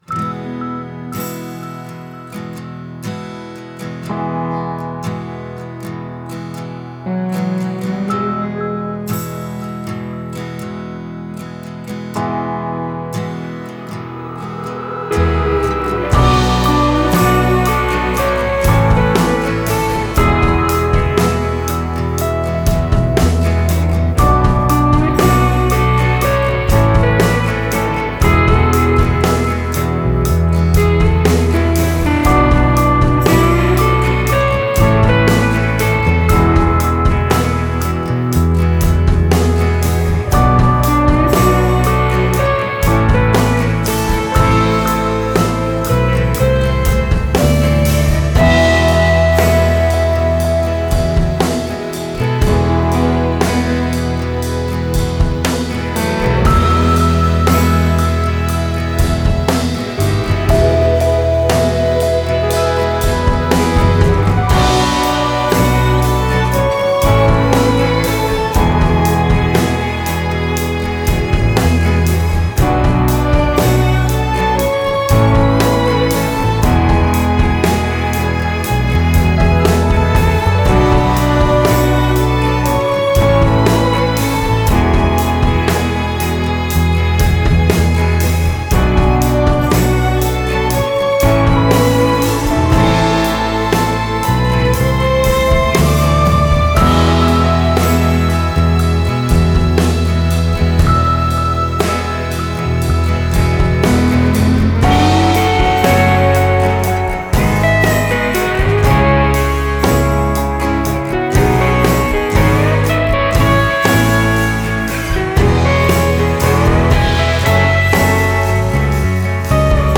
Genre: Indie Pop-Rock / Indie-Folk /